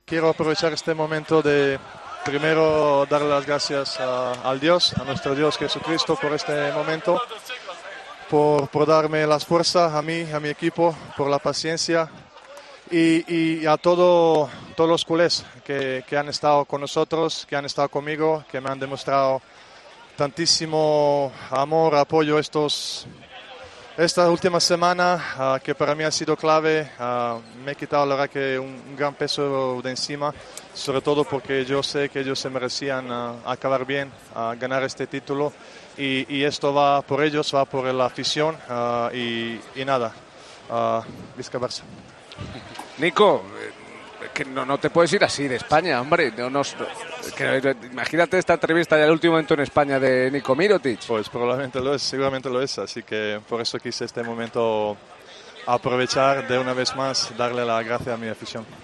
AUDIO: El jugador del Barcelona habló en Movistar tras ganar la Liga ACB y el título mejor jugador de la final, confirmando que se marchará del club azulgrana.